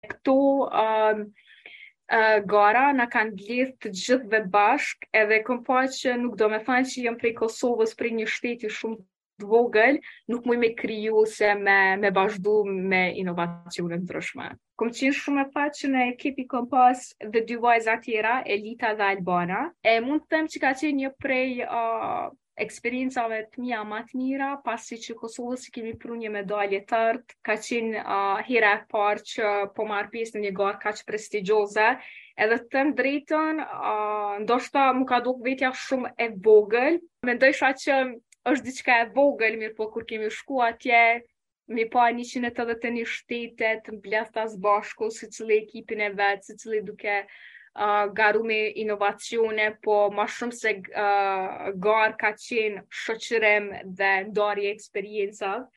Deklarata